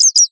minecraft / sounds / mob / bat / idle1.ogg